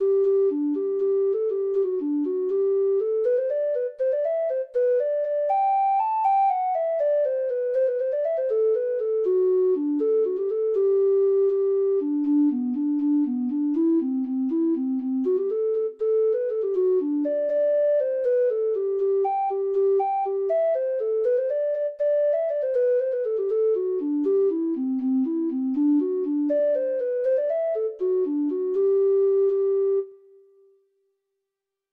Traditional Trad. Planxty O'Flynn (Irish Folk Song) (Ireland) Treble Clef Instrument version
Traditional Music of unknown author.
Irish